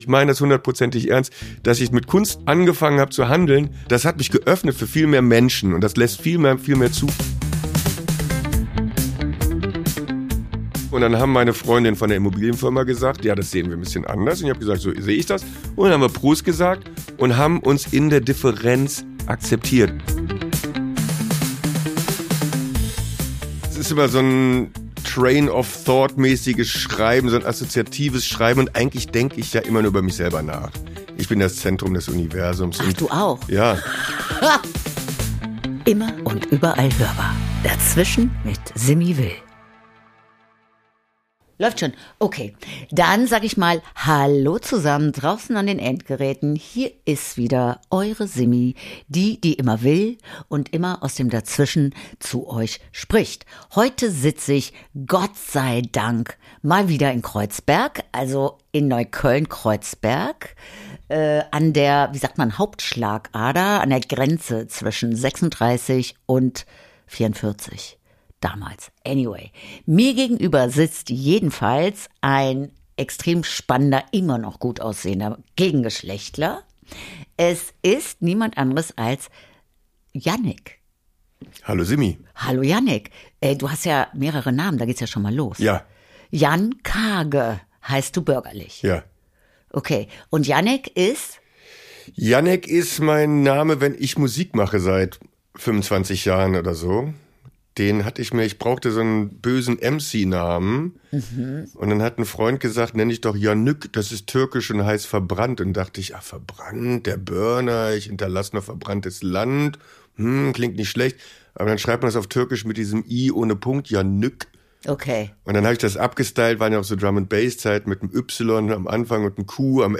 Ein Gespräch über das Leben im „Dazwischen“, persönliche Entwicklungen, politische Haltungen, Kunstmärkte – und warum man manchmal mit einer goldenen Klobürste segnet.